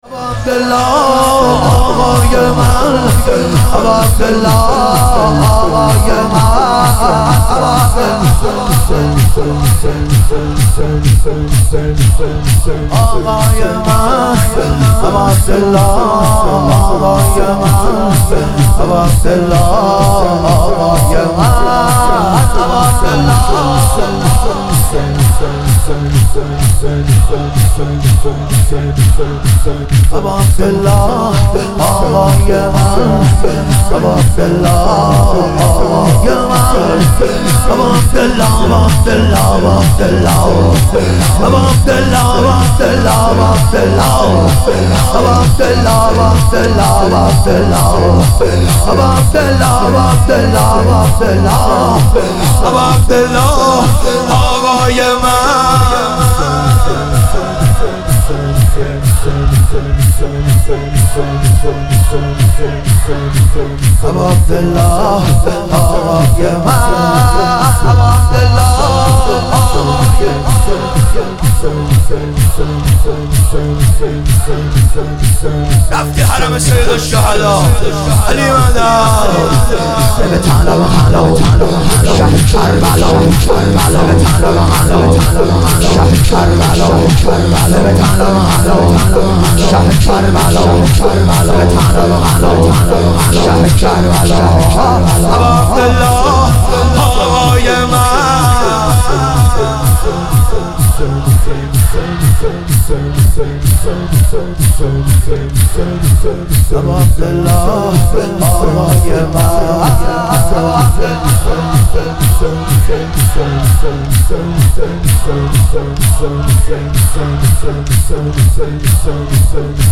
شهادت امام حسن مجتبی علیه السلام - شور